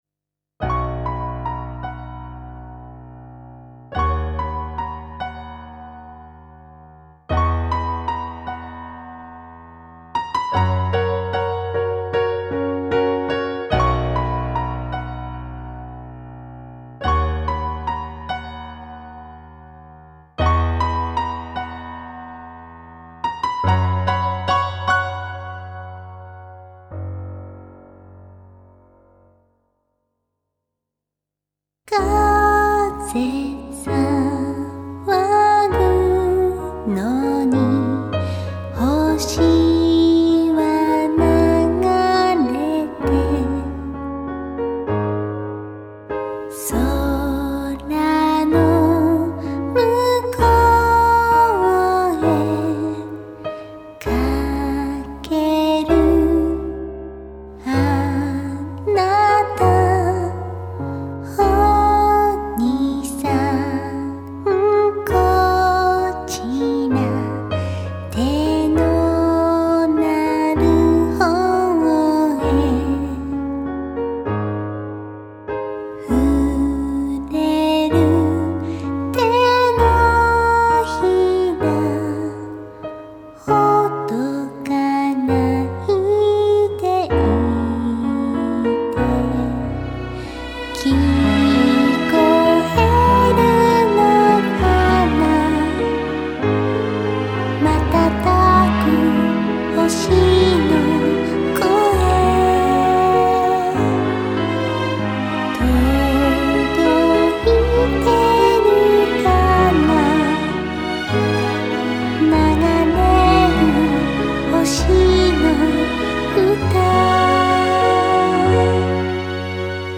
込められた心情をより深く表現したいと思い、シンプルながら難易度の高いピアノ&ストリングスのアレンジに挑戦してみました。